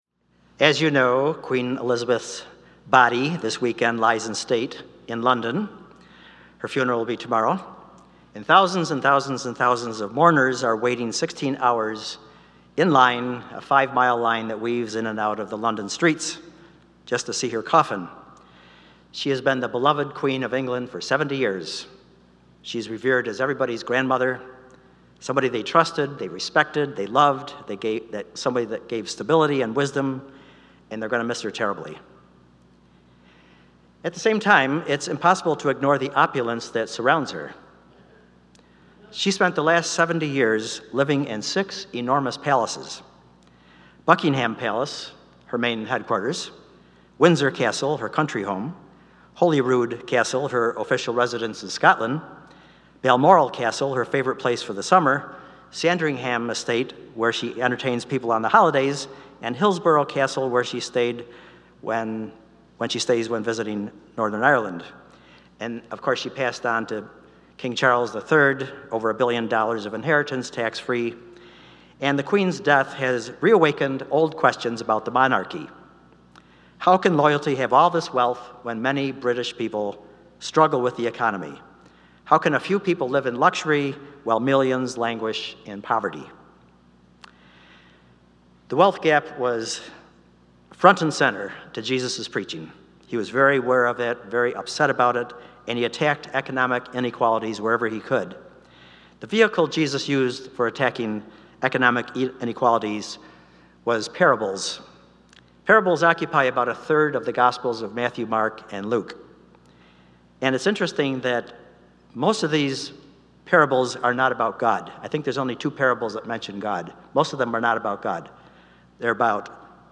I’m so inspired by these homilies.